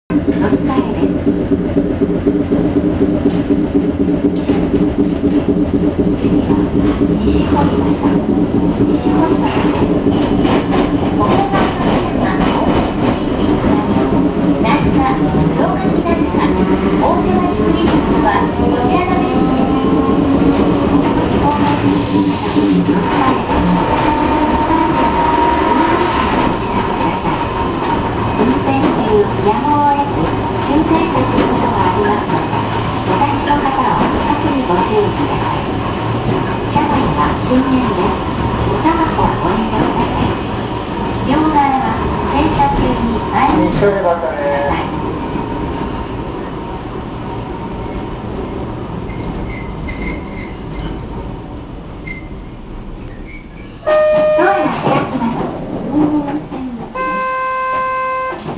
・2000形走行音
【大手町線】大手町→西堀端（58秒：319KB）…2006号にて
当然ながら吊り掛け式ですが、50形と比べれば騒々しさは幾分マシなように思えます。ドアブザーも50形の物と比べればいくらか落ち着いた音になっています。